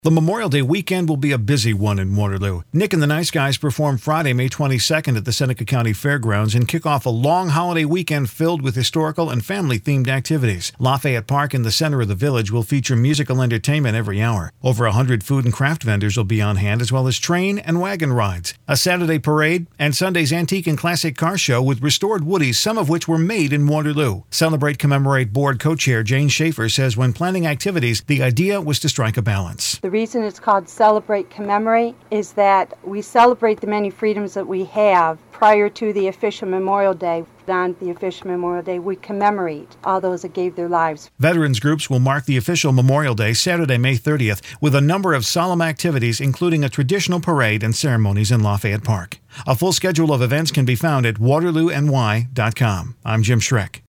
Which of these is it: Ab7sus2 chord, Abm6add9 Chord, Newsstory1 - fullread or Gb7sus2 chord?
Newsstory1 - fullread